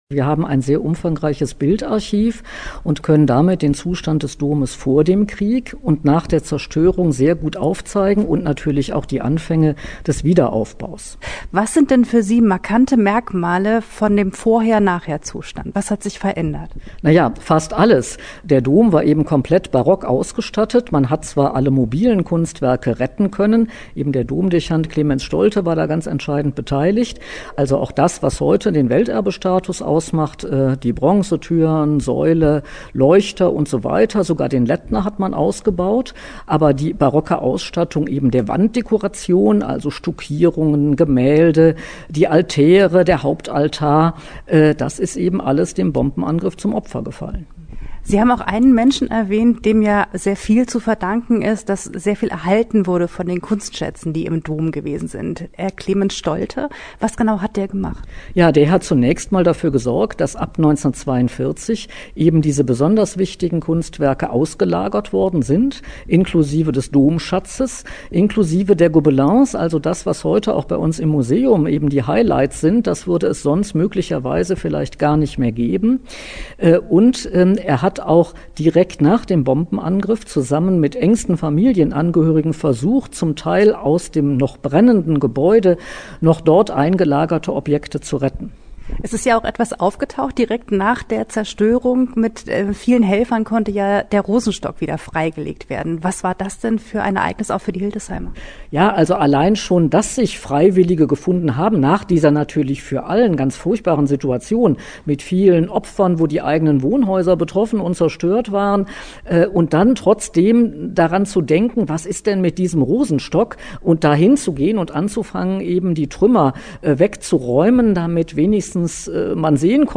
Interview-Dommuseum-Hildesheim.mp3